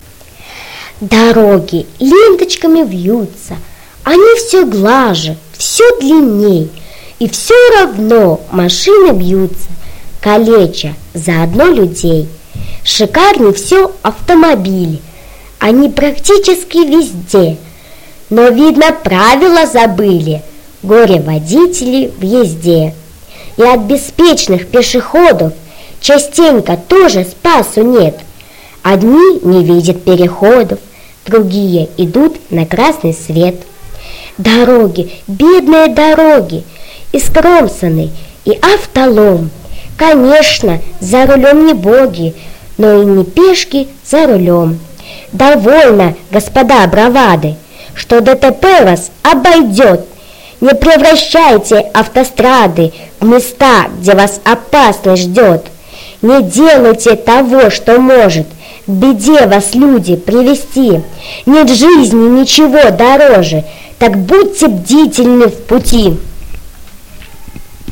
Можно ли убрать шум, который у нас получился при записи,чтоб голос был чистый.